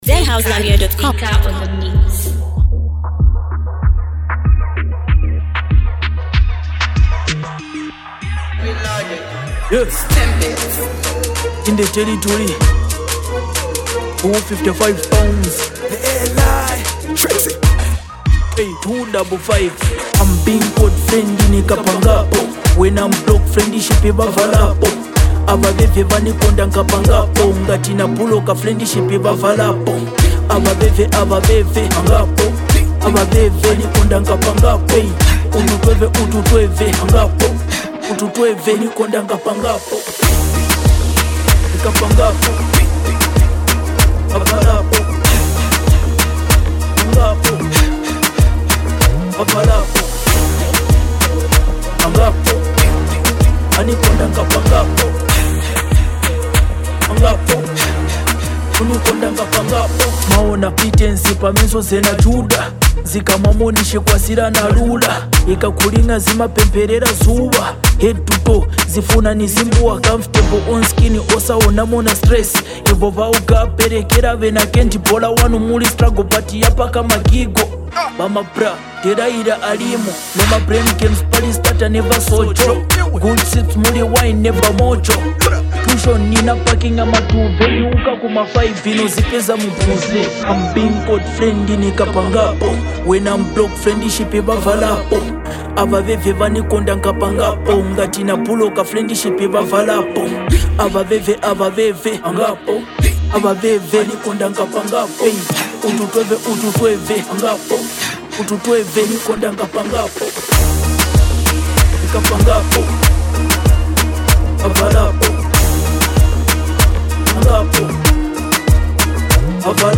a track that blends heavy bars with uplifting vibes.
With a catchy hook and solid verses
A true motivation jam for the streets!